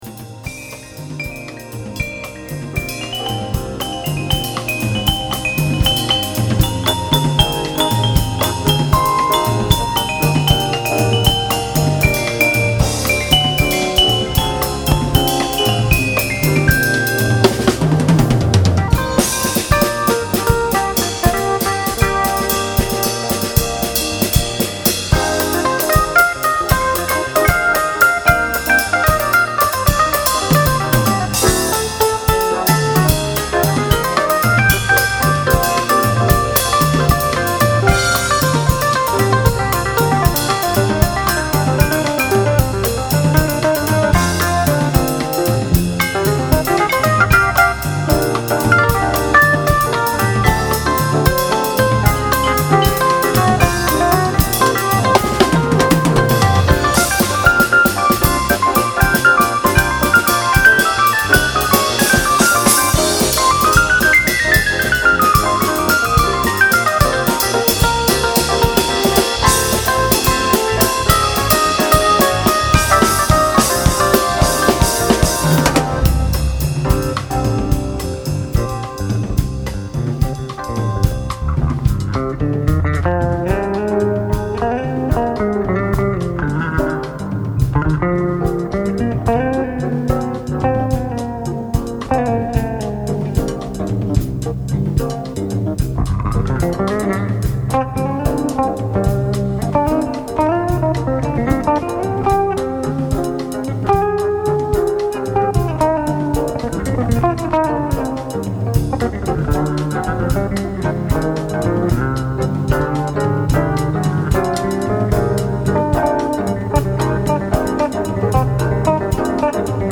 Afro-Cubain – Latino-Jazz
Vibraphone & Percussion
Piano
Basse
Batterie 1992